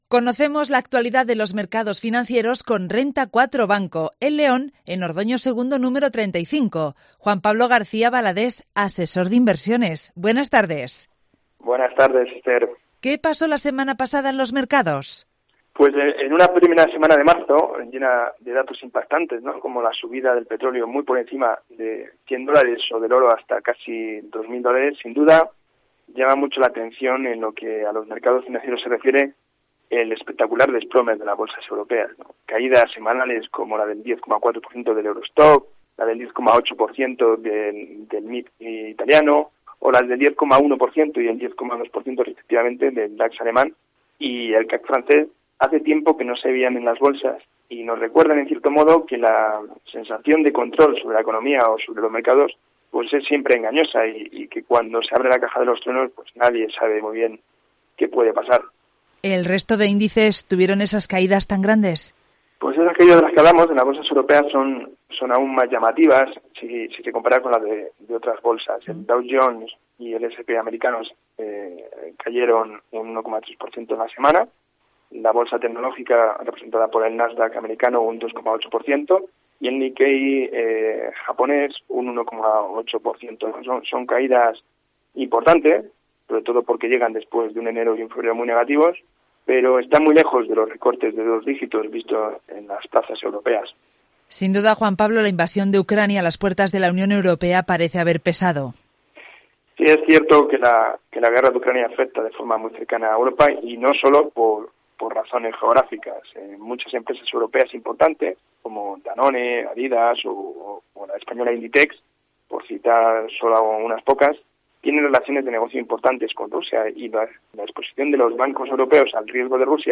Marzo comienza con un espectacular desplome en las Bolsas Europeas.